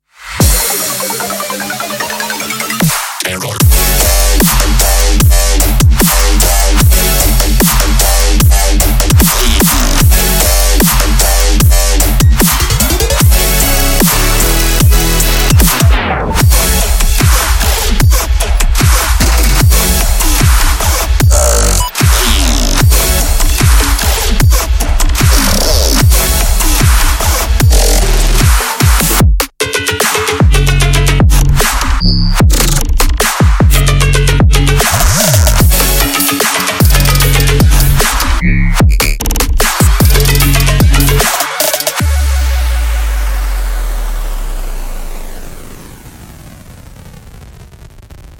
-踢
-军鼓
-低音和声音
-808年代